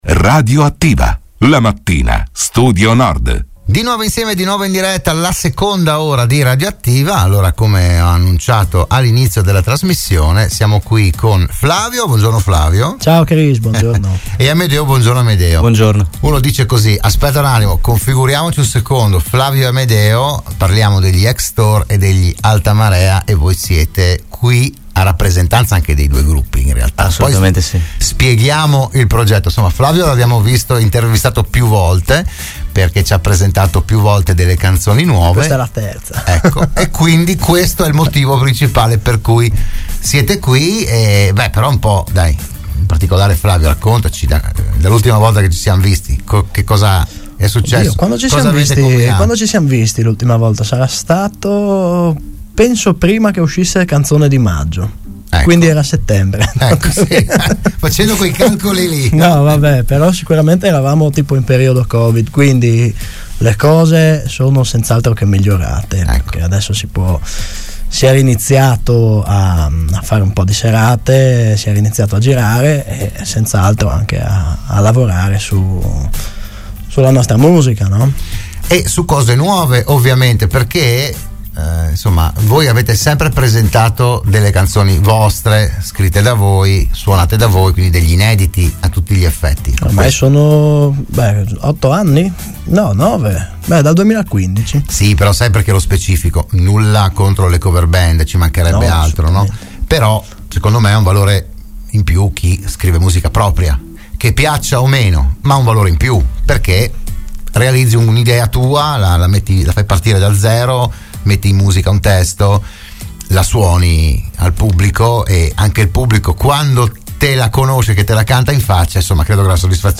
Proponiamo l’audio e il video dell’intervista e, più sotto, il videoclip della canzone.